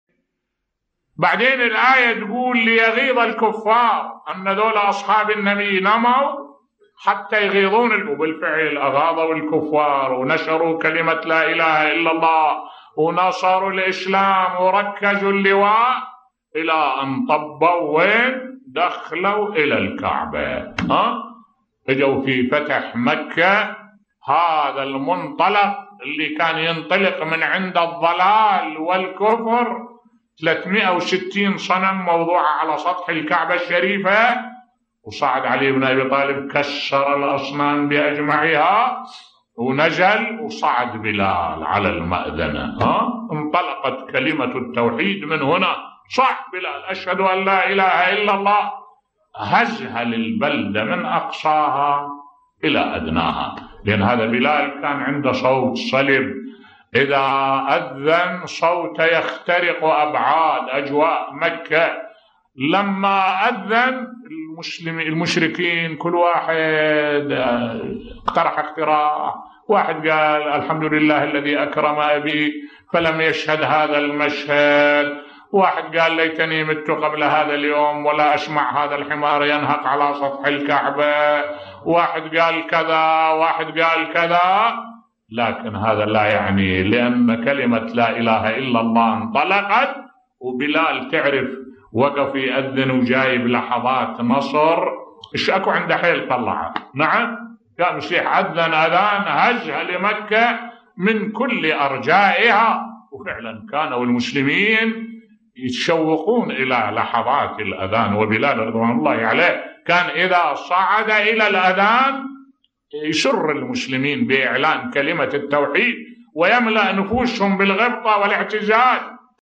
ملف صوتی الصحابة نشروا الاسلام بصوت الشيخ الدكتور أحمد الوائلي